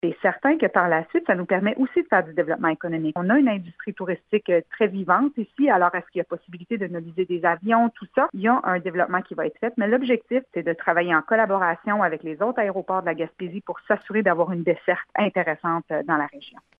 Nadia Minassian rappelle qu’une collaboration entre tous les aéroports de la Gaspésie est de mise pour assurer un bon service :